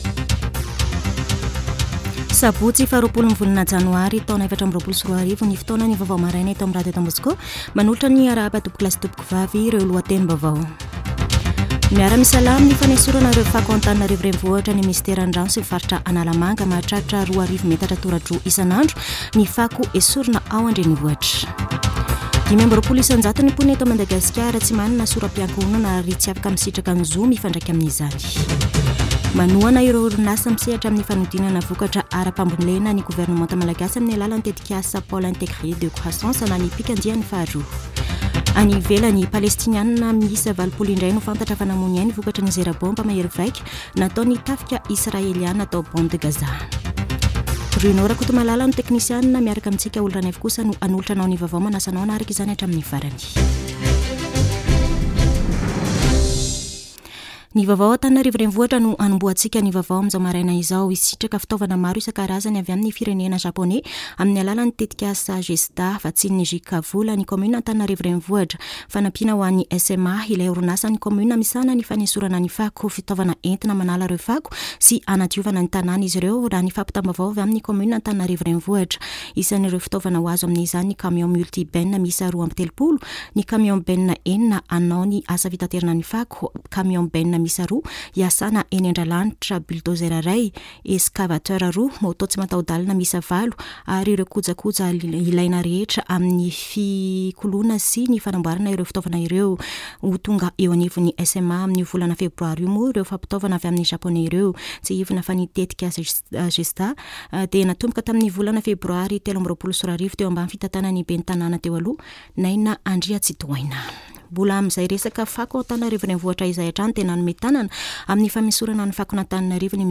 [Vaovao maraina] Sabotsy 20 janoary 2024